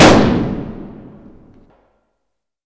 Gunshot 4
Ammo Automatic Explosion Fast Gunshot Layered Loud Machine-Gun sound effect free sound royalty free Memes